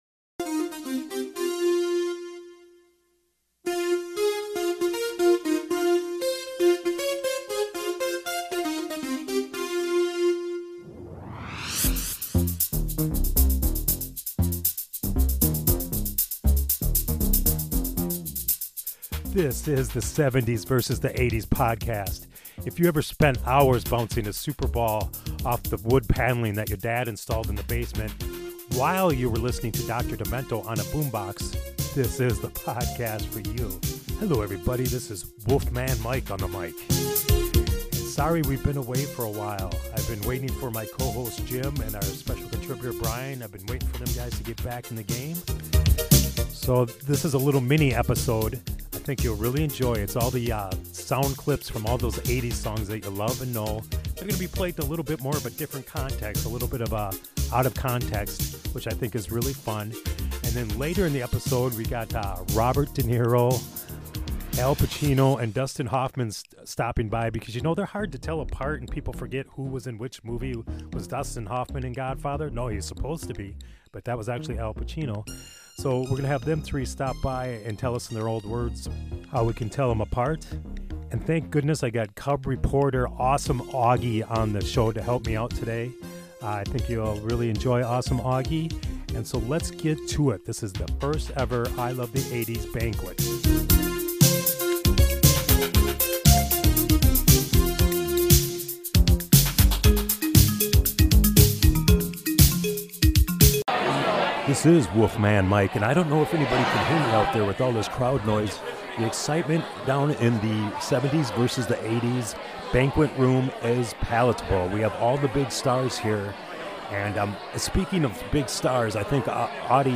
Sounds clips craziness episode! We start out in the first ever I Love The 80's Banquet.